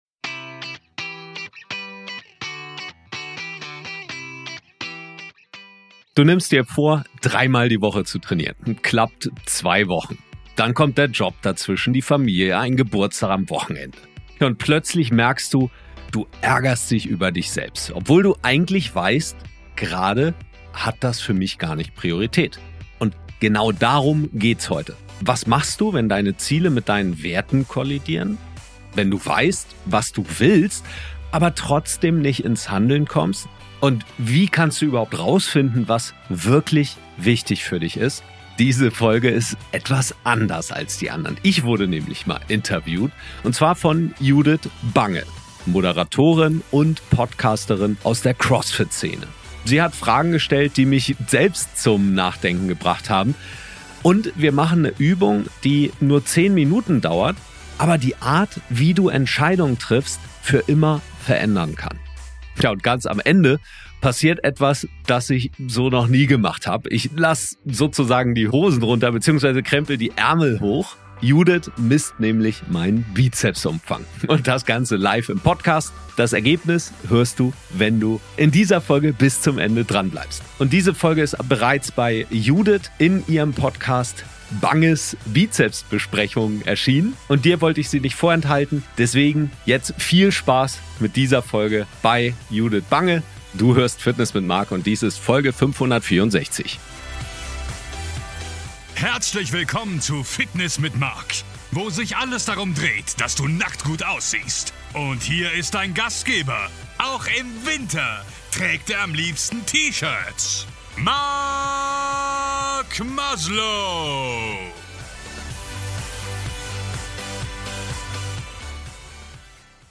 Beschreibung vor 3 Wochen Diese Folge ist anders: Ich wurde interviewt.